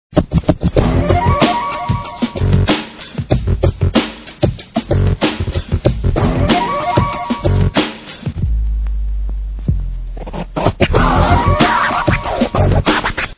this is hip hop ffs !!!